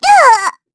Xerah-Vox_Damage_kr_01_Madness.wav